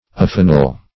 (anthropology) related by marriage ; [syn: affinal , affine ] The Collaborative International Dictionary of English v.0.48: Affinal \Af*fi"nal\, a. [L. affinis.]
affinal.mp3